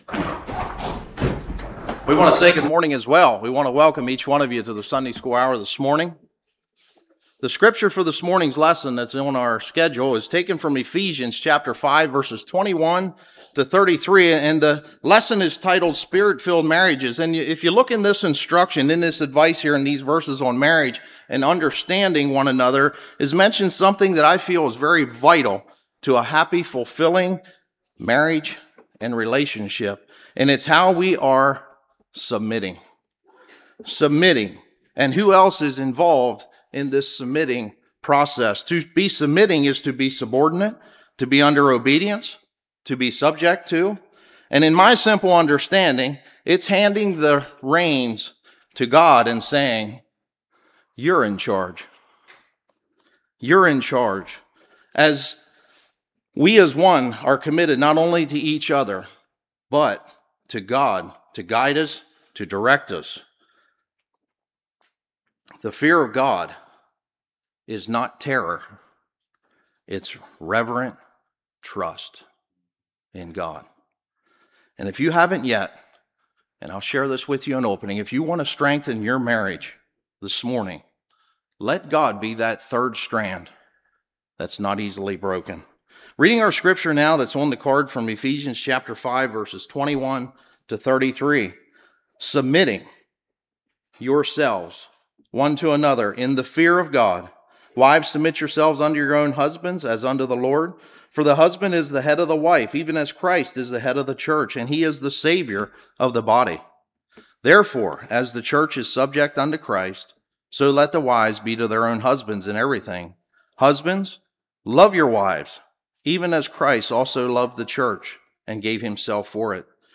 Ephesians 5:21-33 Service Type: Sunday School Practical Ways to Love Each Other Tools of marriage.